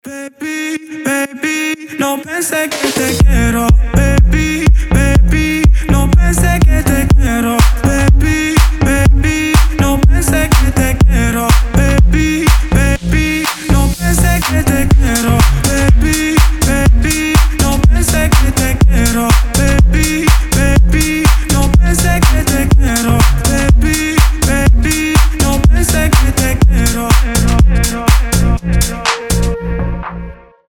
• Качество: 320, Stereo
slap house